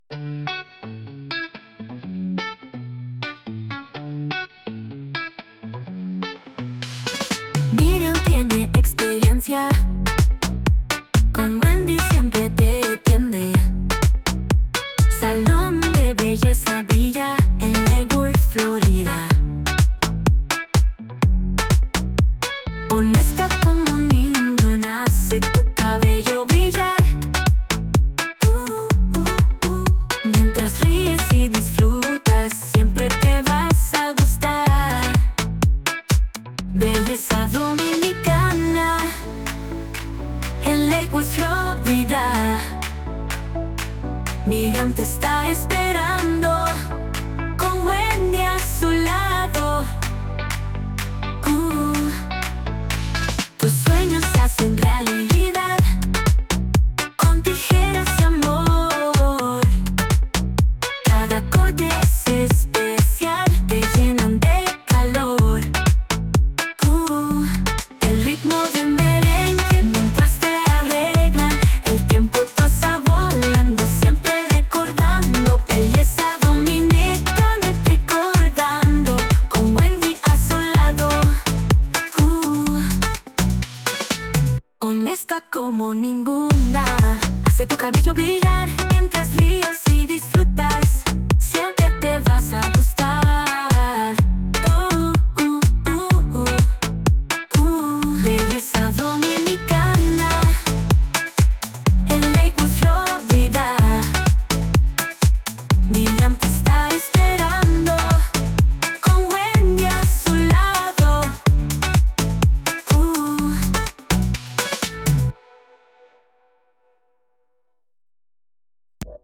Music Genre: Merengue Pop (Second Version)